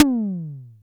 CLICK E-TOM.wav